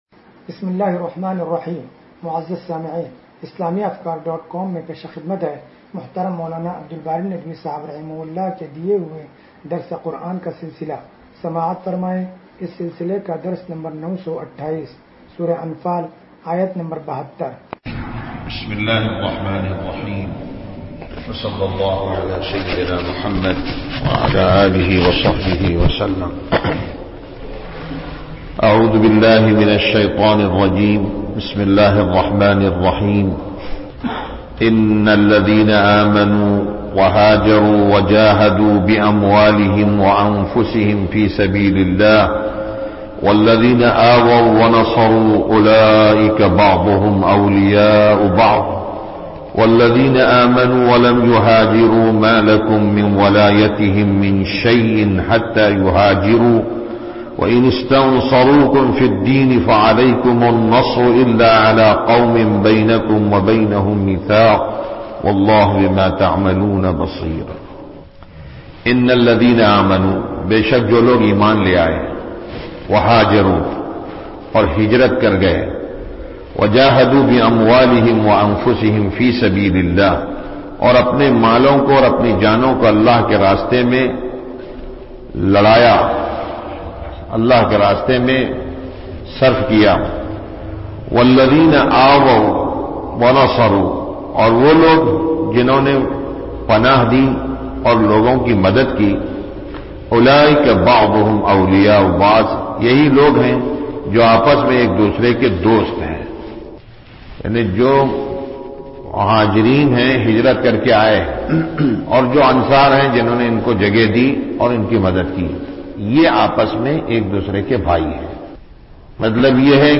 درس قرآن نمبر 0928